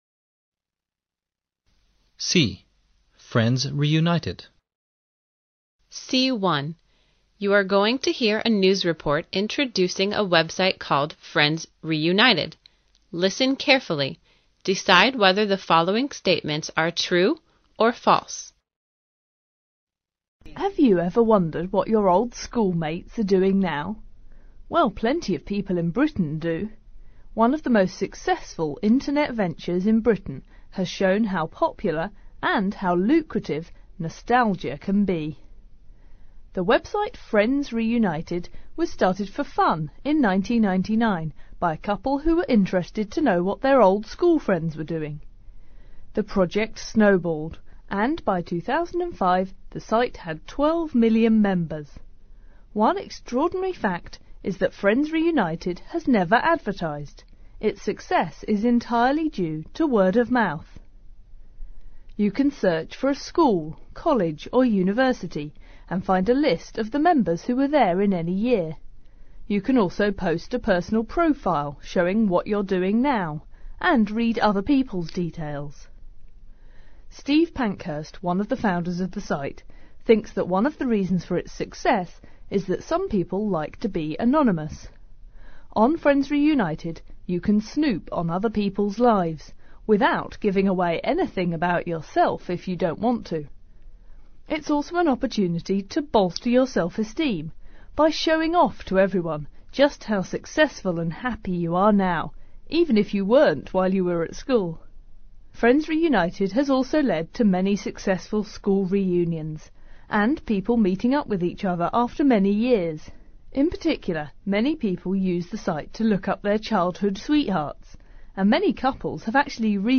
C1. You're going to hear a news report introducing a website called Friends Reunited.